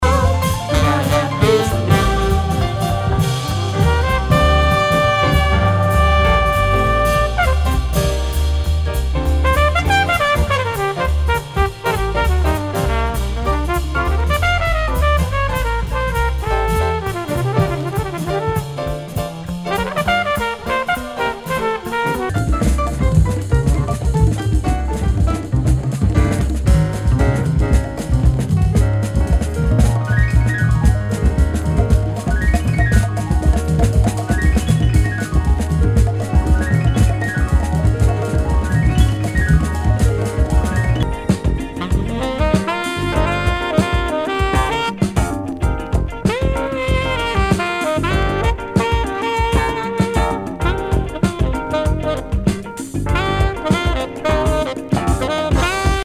Nu- Jazz/BREAK BEATS
スピリチュアル / クラブ・ジャズ・クラシック！
全体にチリノイズが入ります。